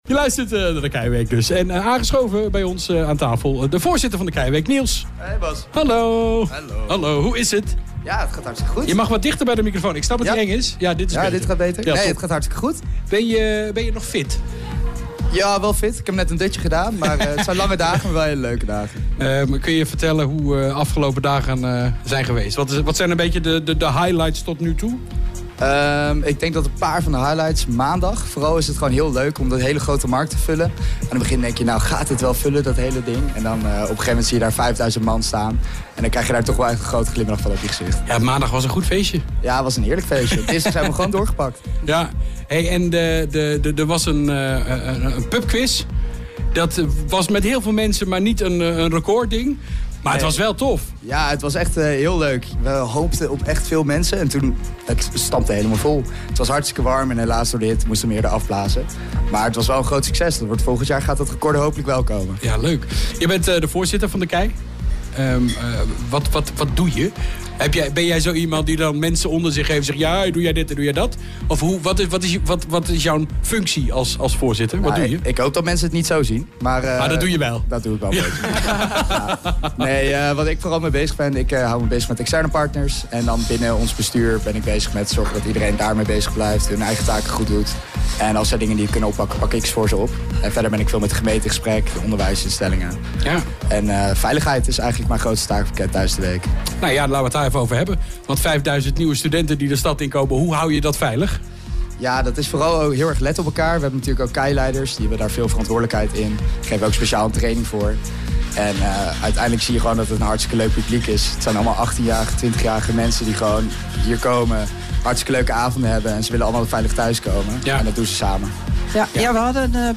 nieuws
Live Radio